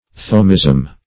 thomism - definition of thomism - synonyms, pronunciation, spelling from Free Dictionary
Thomism \Tho"mism\, Thomaism \Tho"ma*ism\, n. (Eccl. Hist.)